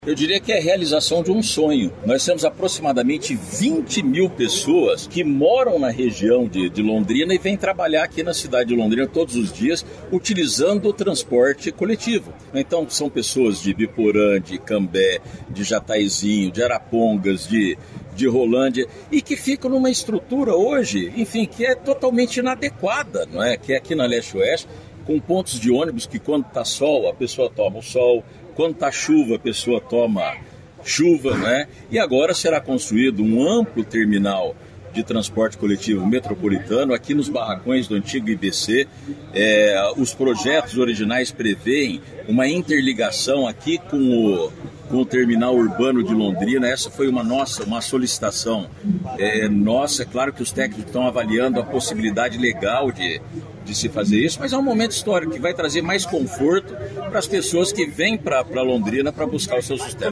Sonora do prefeito de Londrina, Marcelo Belinati, sobre sobre o Terminal Metropolitano da cidade